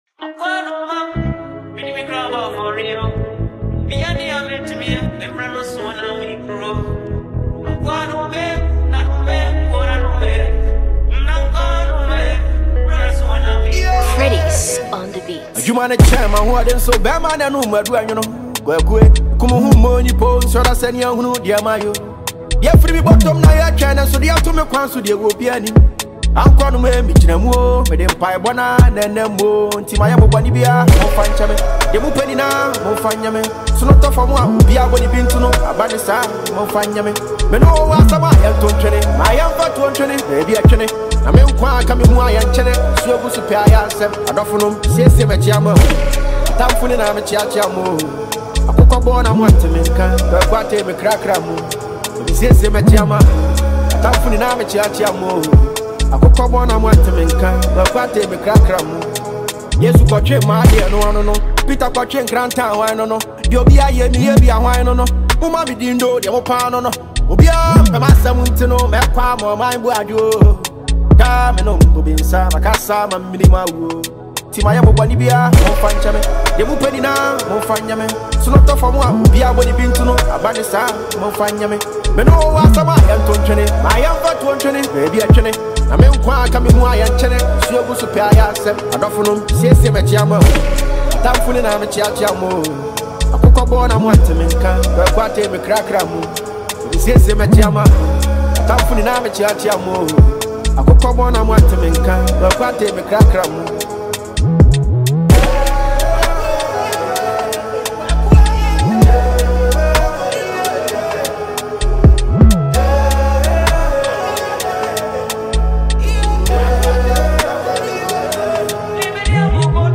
A perfect balance of lyrical maturity and melodic warmth
a spiritual journey wrapped in a hip-hop and Afrobeat shell